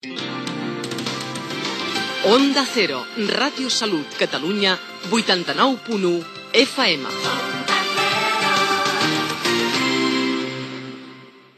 Indicatiu de l'emissora